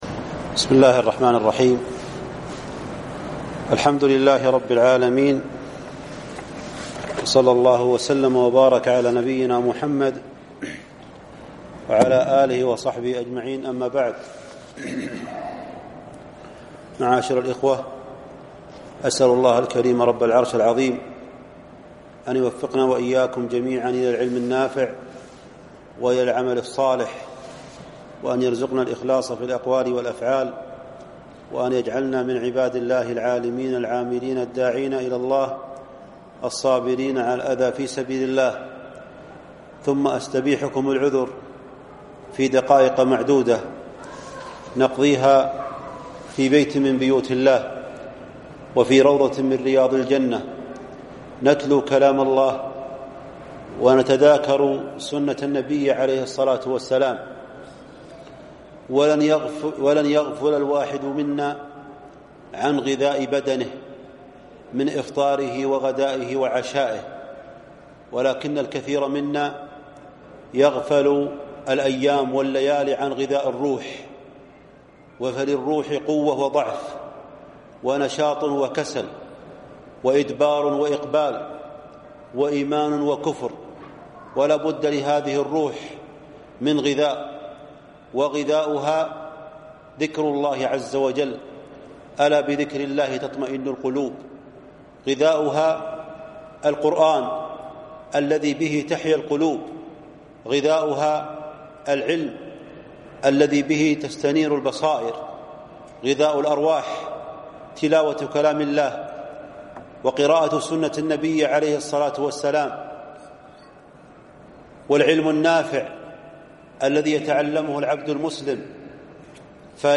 غذاء الروح وأسباب الفلاح - كلمة